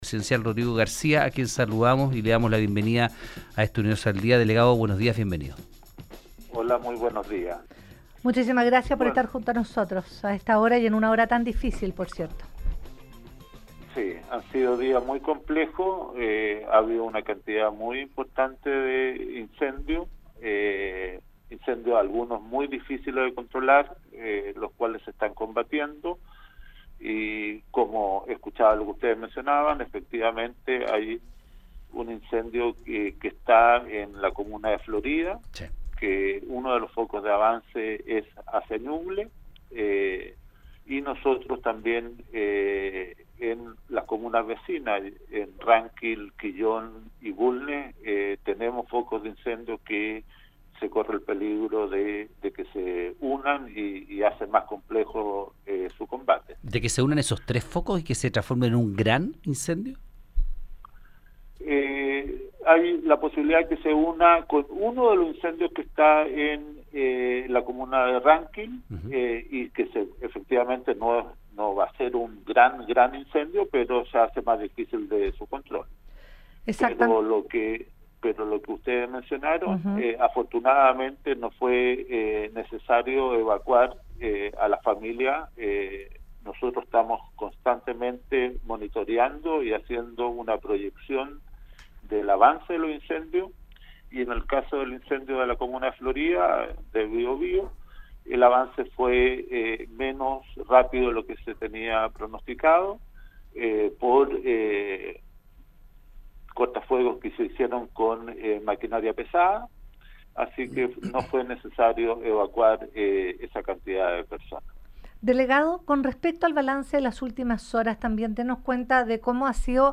Hoy en Universo al Día, el delegado presidencial de Ñuble, Rodrigo García, advirtió en que la región enfrenta una jornada compleja debido a los incendios forestales activos, especialmente en Ránquil y Portezuelo, con riesgo adicional por el avance de un foco desde la comuna de Florida, en el Biobío.